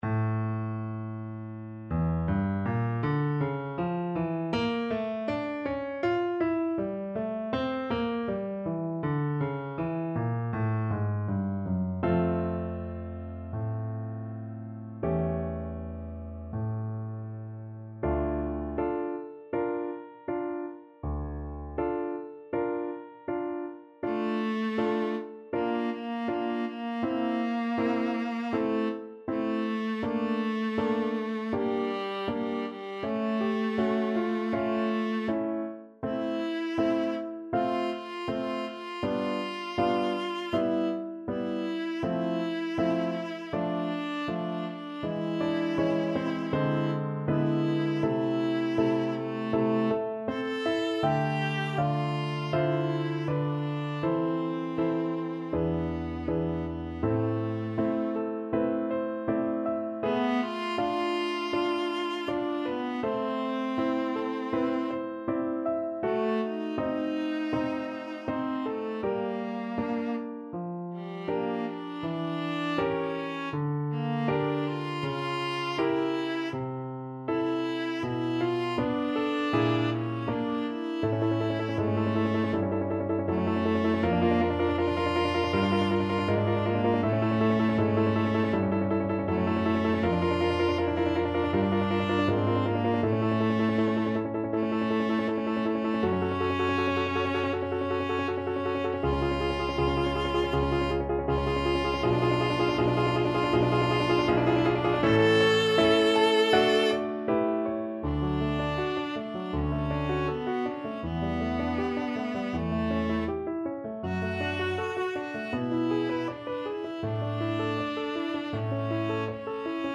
Viola
D minor (Sounding Pitch) (View more D minor Music for Viola )
4/4 (View more 4/4 Music)
~ = 100 Molto moderato =80
Classical (View more Classical Viola Music)